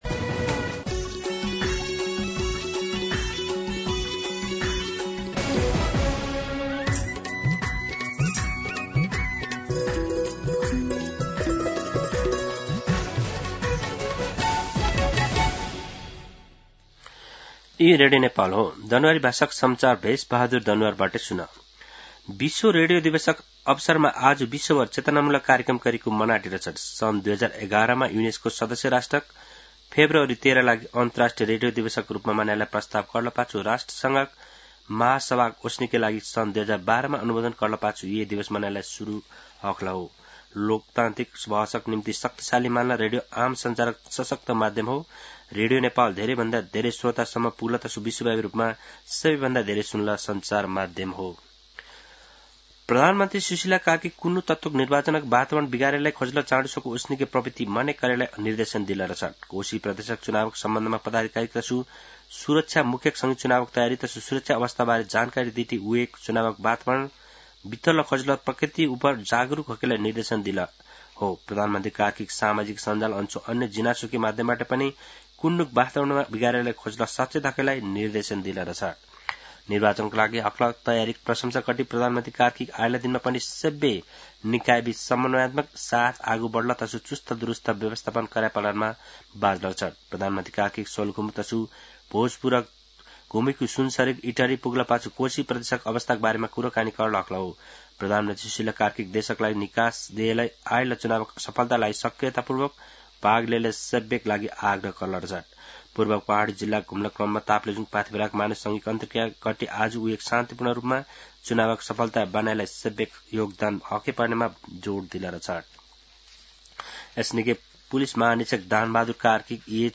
दनुवार भाषामा समाचार : १ फागुन , २०८२